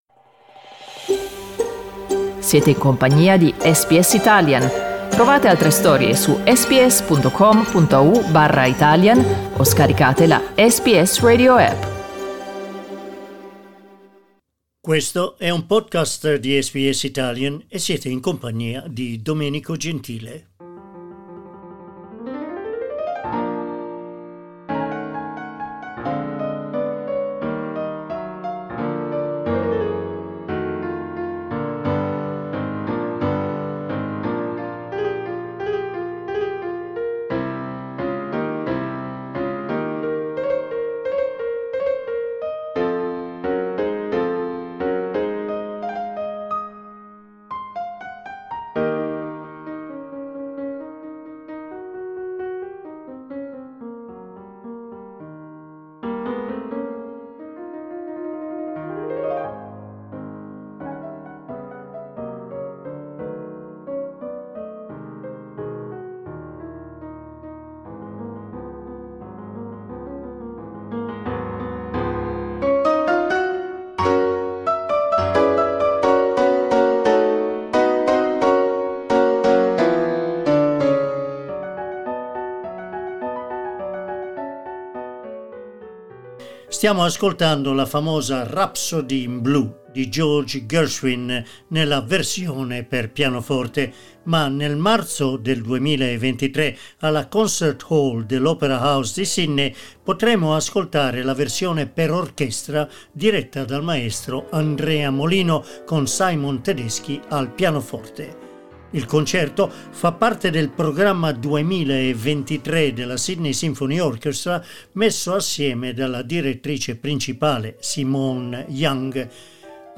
Credits: George Gershwin's "Rhapsody in Blue" piano solo.